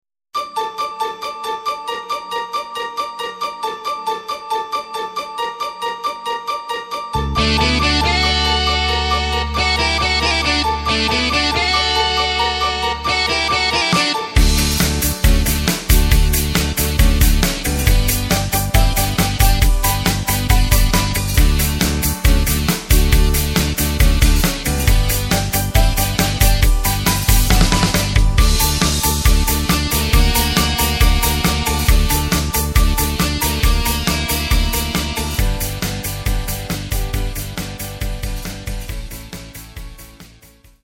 Takt:          4/4
Tempo:         137.00
Tonart:            D
Playback mp3 Demo